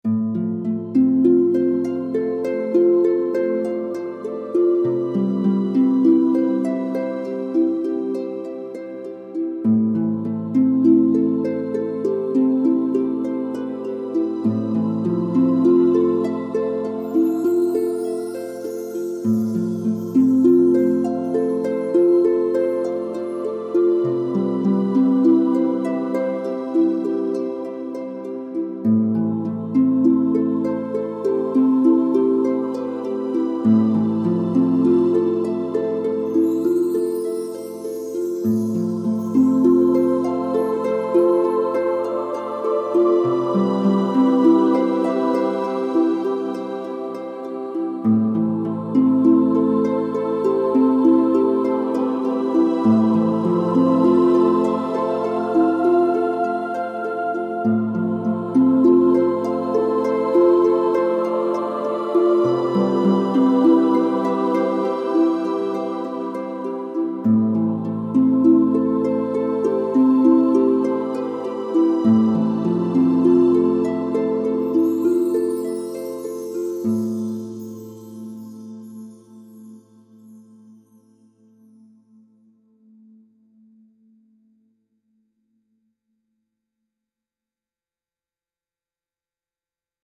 transcendent ethereal music with heavenly choir pads and gentle harp